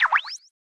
monitorOn.ogg